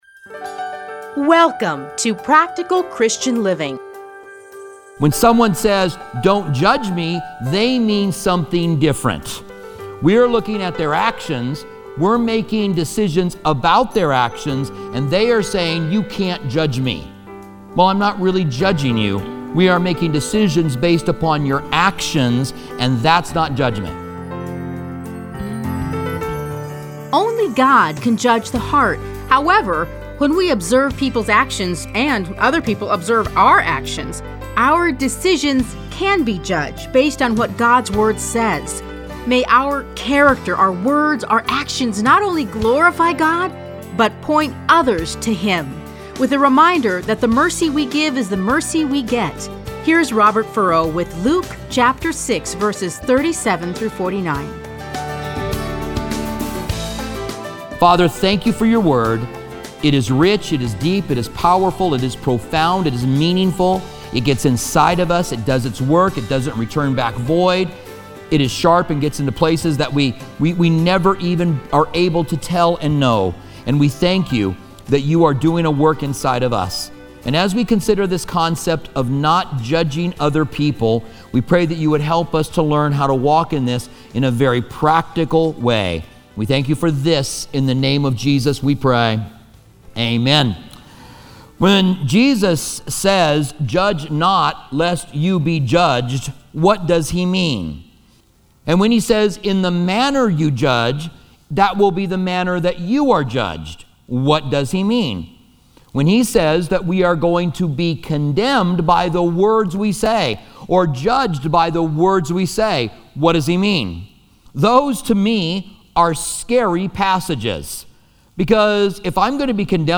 Listen to a teaching from Luke 6:37-49.